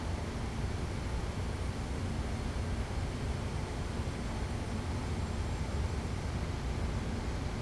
air_conditioner.R.wav